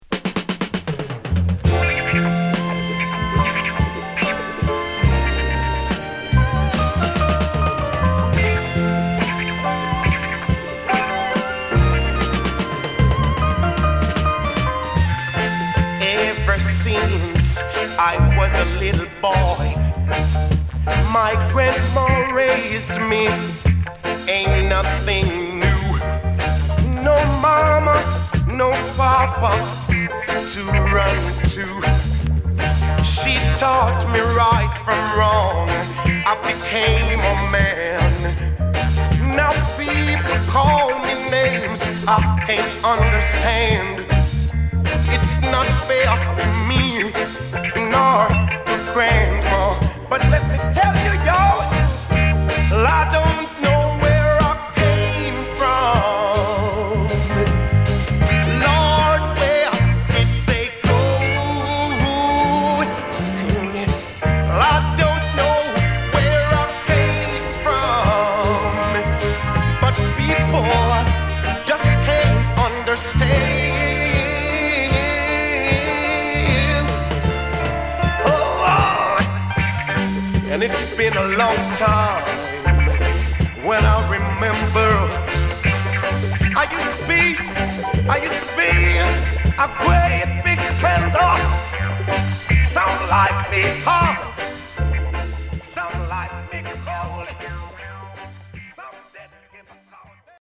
流麗なイントロからグルーヴィーなベースが牽引するトラックで、流石のヴォーカルを聴かせるソウルフルな1曲！